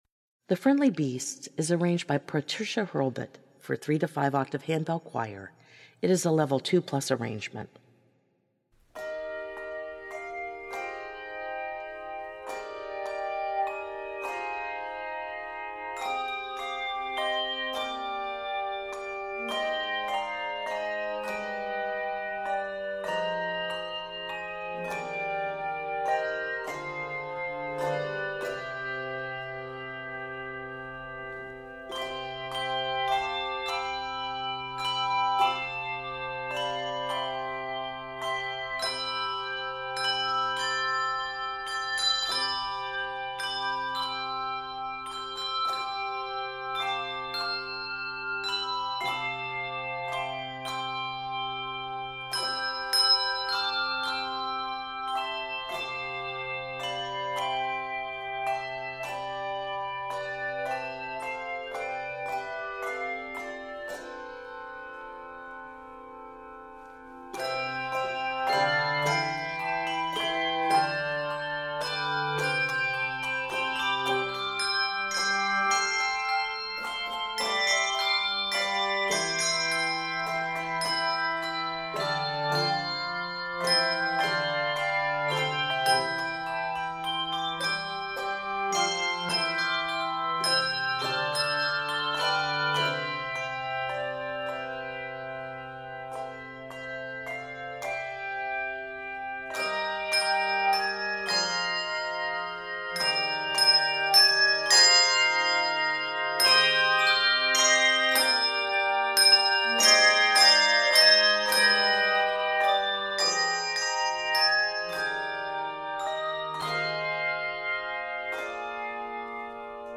Octaves: 3-5
2019 Season: Christmas